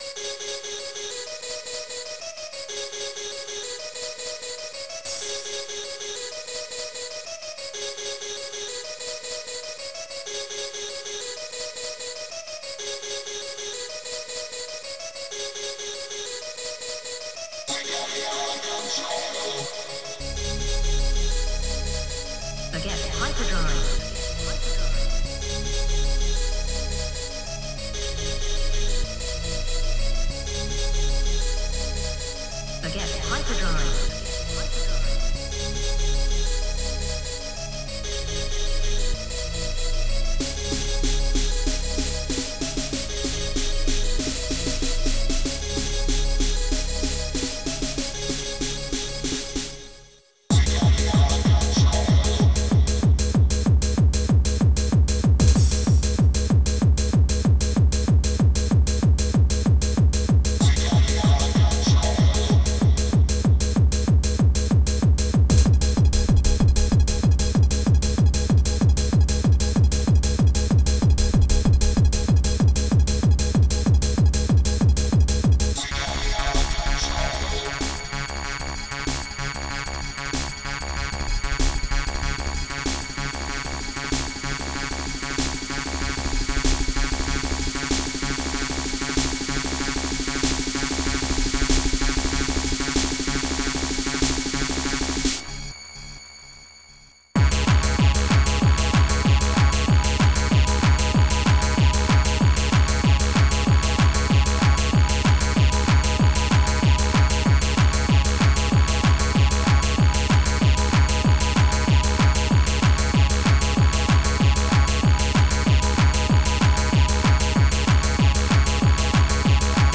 Protracker Module
2 channels
hardtrance